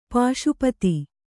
♪ pāśupati